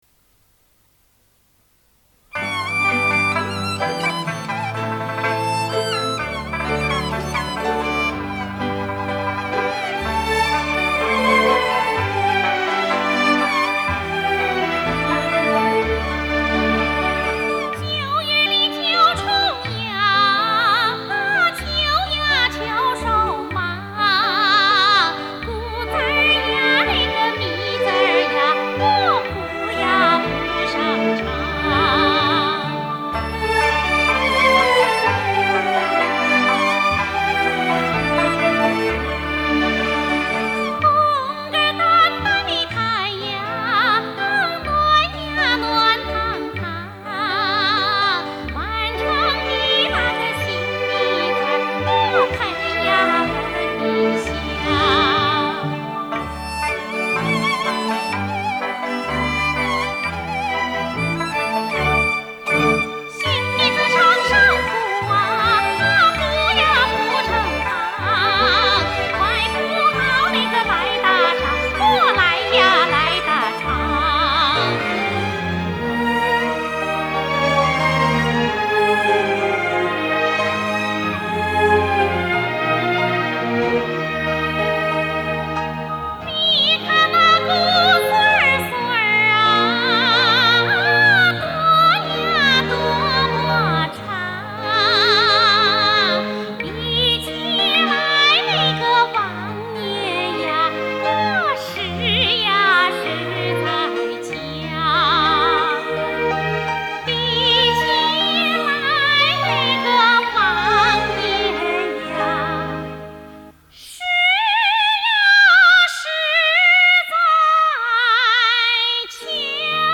陕西民歌